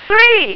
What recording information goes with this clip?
I used "microcomputer control mode" to play each of the 8 samples while capturing the data at the two PWM outputs, then converted them to WAV format: Sound 0 Sound 1